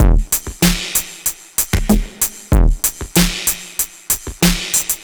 Index of /musicradar/analogue-circuit-samples/95bpm/Drums n Perc
AC_MachineDrumsB_95-97.wav